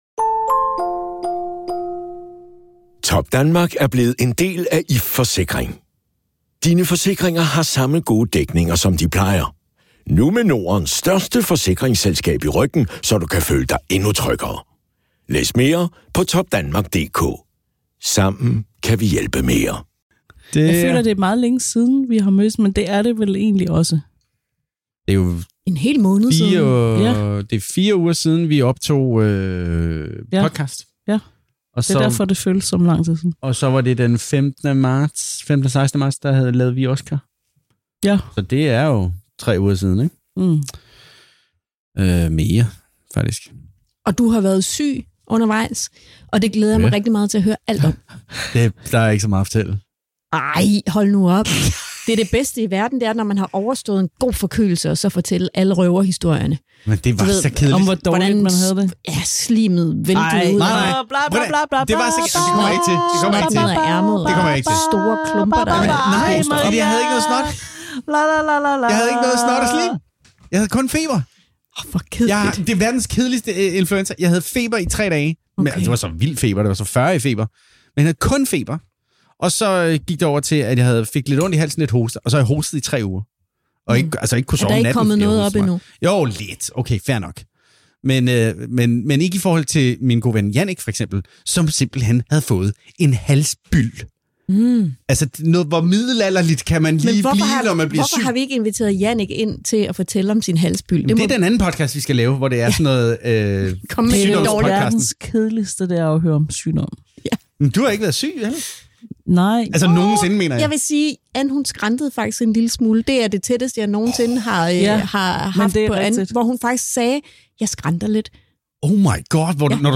Som noget helt nyt har vi nemlig optaget hele episoden på video, så hvis du vil se vores ansigter i studiet, ligger hele afsnittet klar på vores YouTube-kanal.